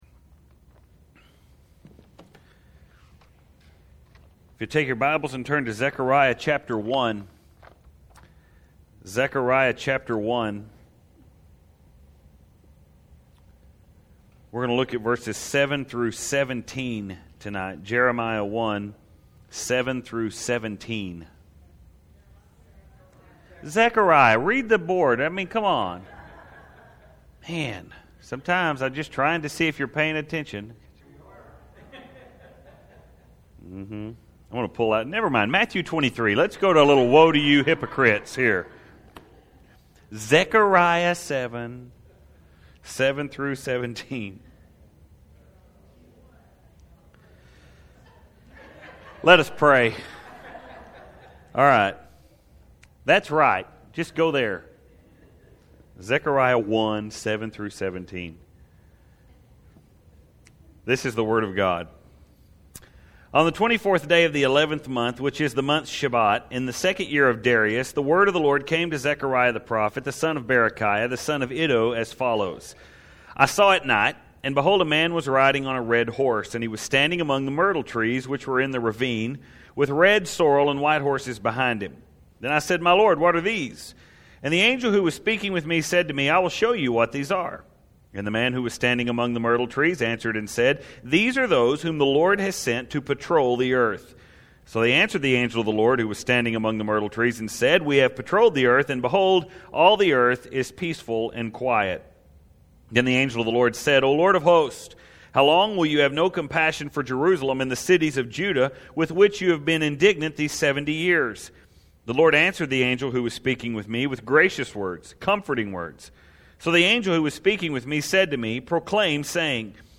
Tonight we move a step forward to the next major sermon from Zechariah.